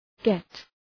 {get}